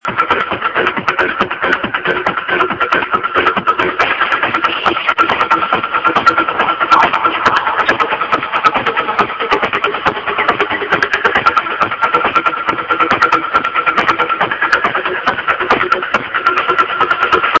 18 seconds unknown track, low quality.